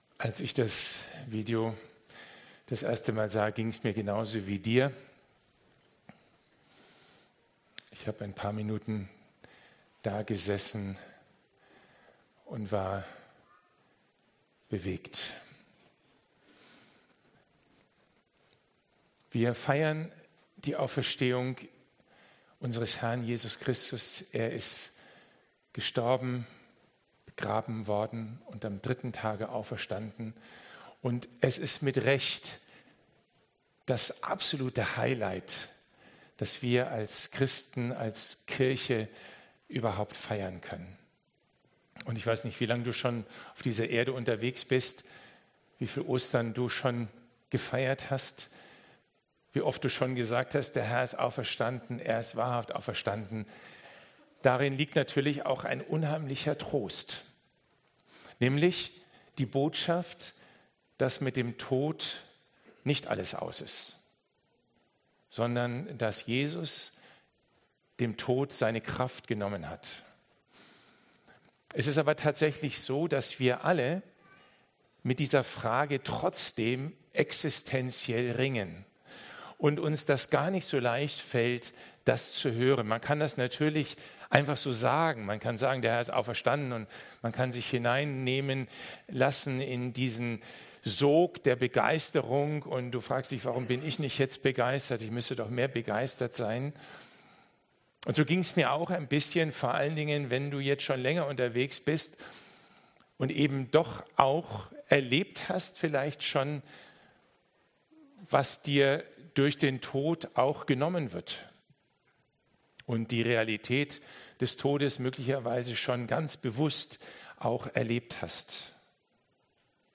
Predigt-Zusammenfassung